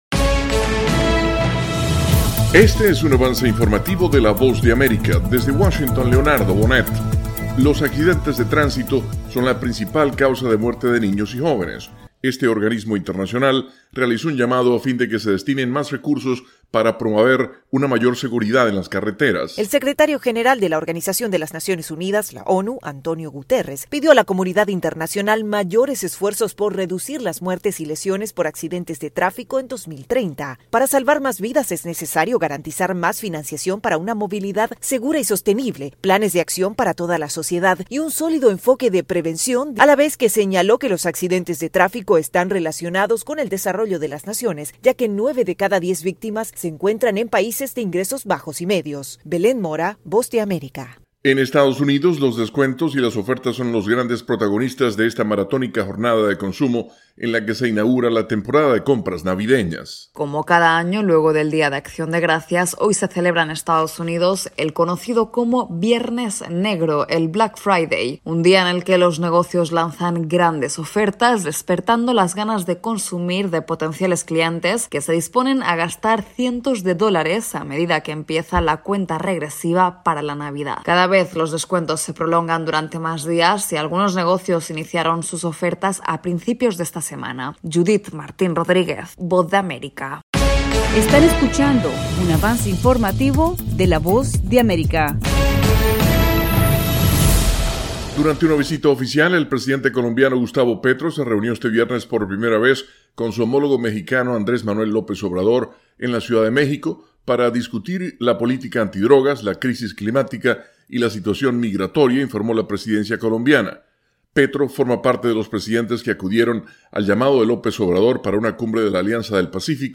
El siguiente es un avance informativo presentado por la Voz de América, desde Washington,